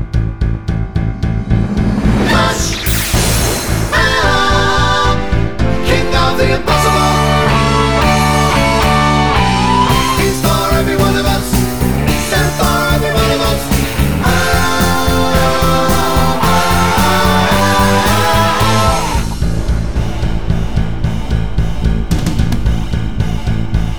no movie clips Rock 2:45 Buy £1.50